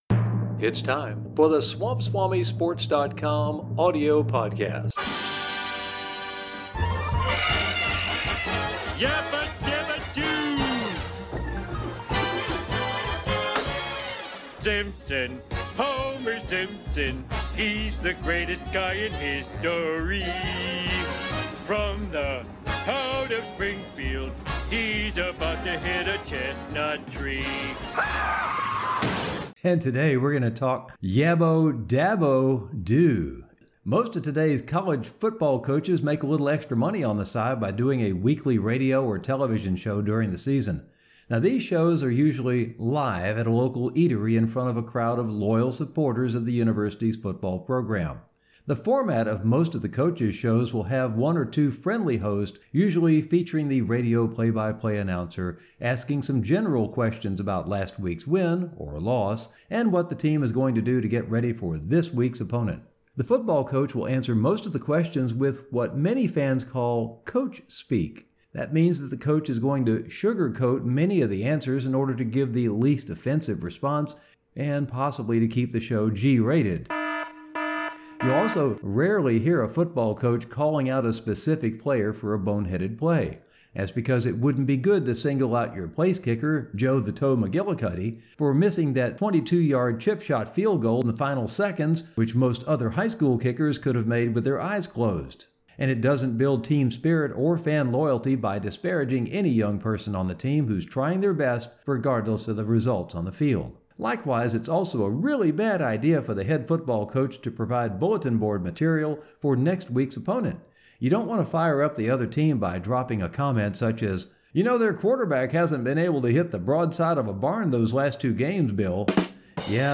Clemson football coach Dabo Swinney launched into an epic 5-minute response to a caller on his radio show Monday.
These shows are usually “live” at a local eatery in front of a crowd of loyal supporters of the university’s football program.
SwampSwamiDaboSwinneyRadioShow.mp3